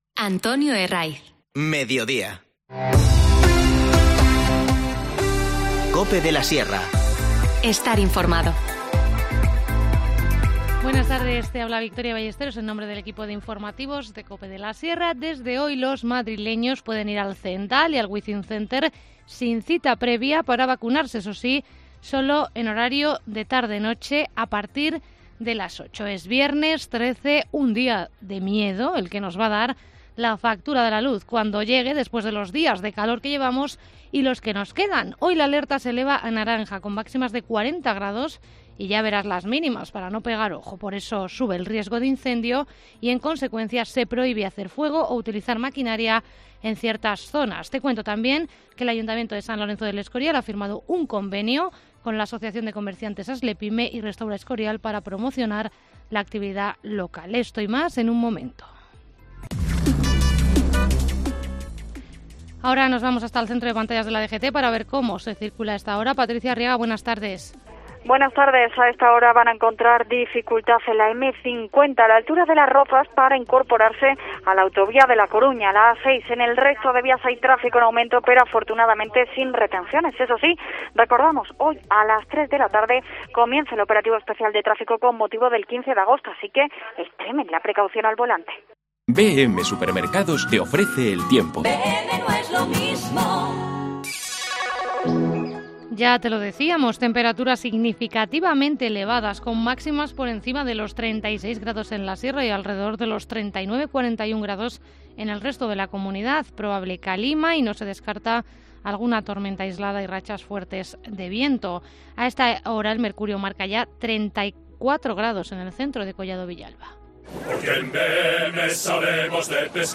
Informativo Mediodía 13 agosto